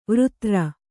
♪ vřtra